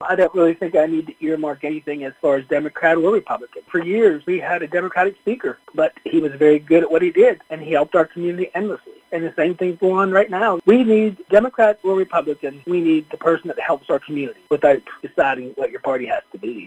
Lonaconing Mayor Jack Coburn spoke with WCBC regarding his decision to endorse Governor Wes Moore’s re-election campaign.  Coburn compared Moore to Cas Taylor, former Maryland Speaker of the House, who was also a democrat.